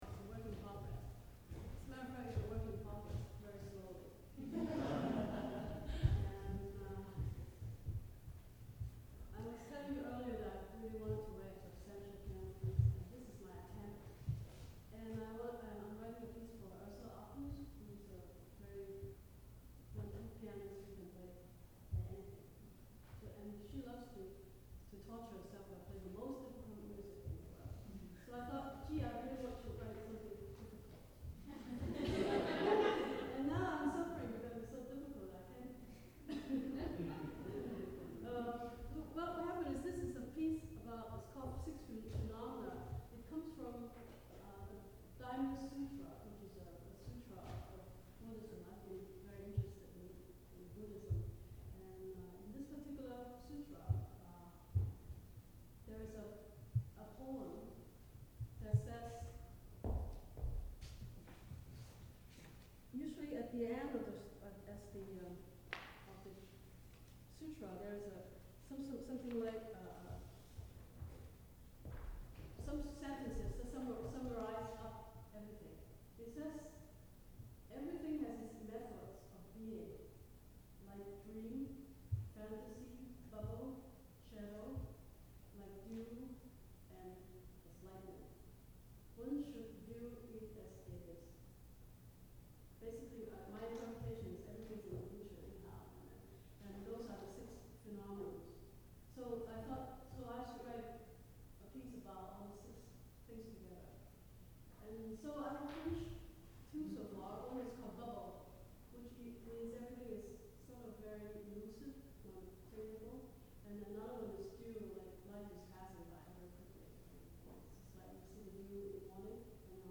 Live recording
in a concert at Bennington College, Vermont.